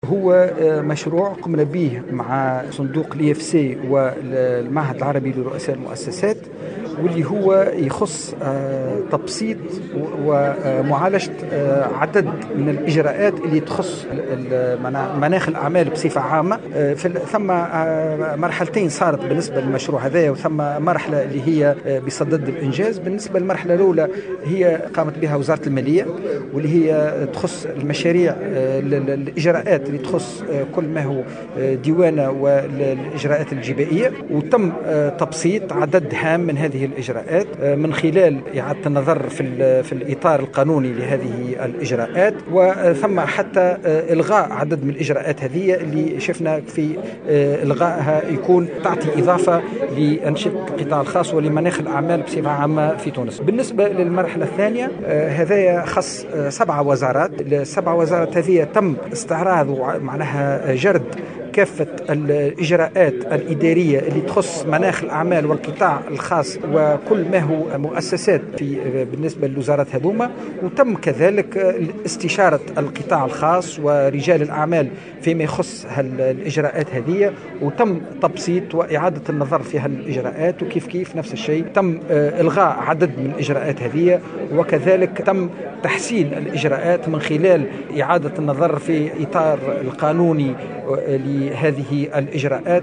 وفي تصريح للجوْهرة كشف مدير عام الإصلاح الإداري برئاسة الحكومة خالد السلامي أنّ التقرير المتعلق بالمرحلة الثانية من مشروع الإصلاح الإداري أوْصى بإلغاء 37 إجراء إداري وتبسيط 493 إجراء آخر.